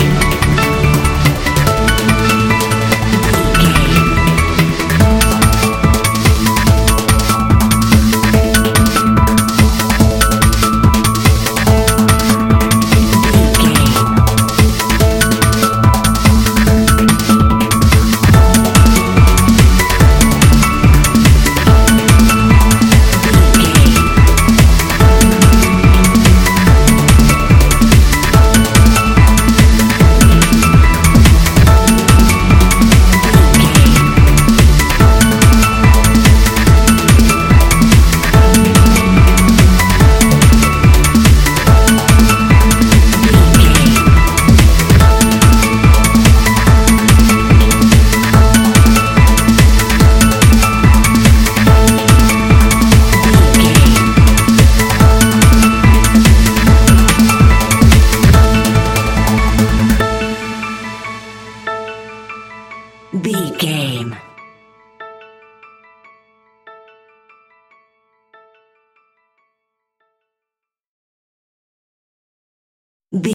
Ionian/Major
Fast
energetic
uplifting
hypnotic
drum machine
piano
synthesiser
acid house
electronic
uptempo
synth leads
synth bass